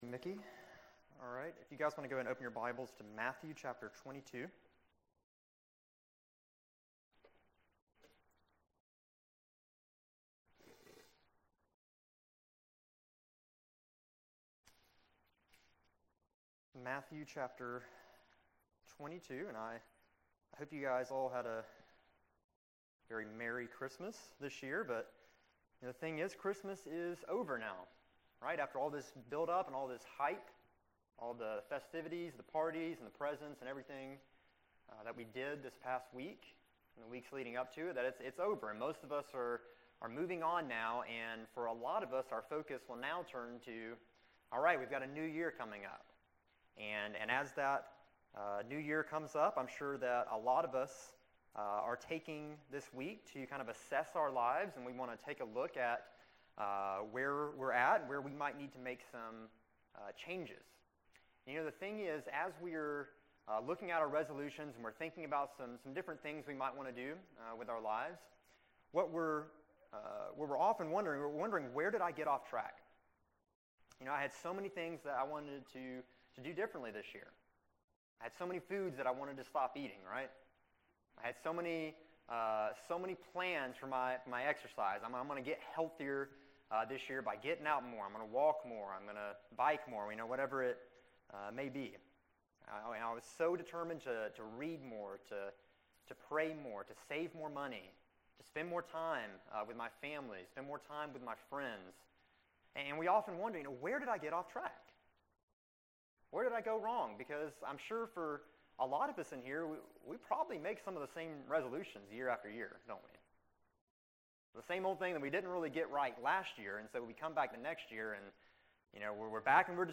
December 27, 2015 Morning Worship | Vine Street Baptist Church
Those gathered heard scripture, sang songs and learned from the Bible.